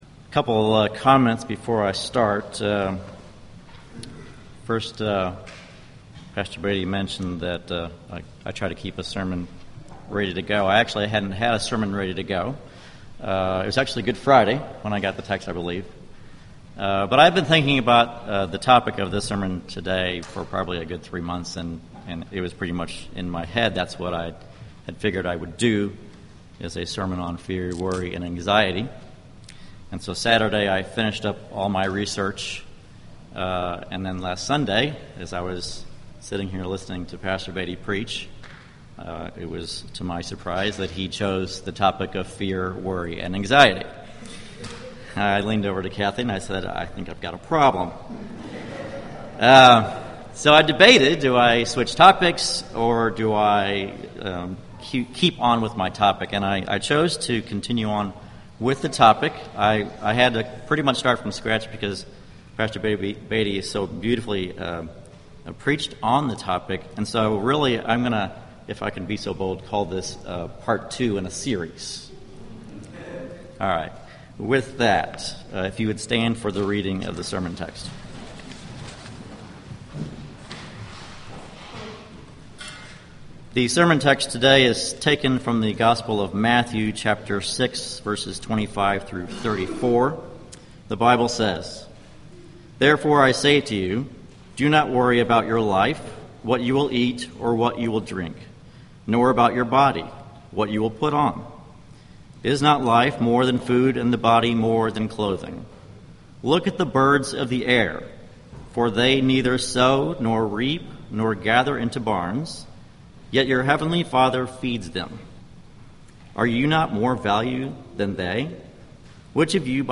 Sermons Apr 27 2014 Preached April 27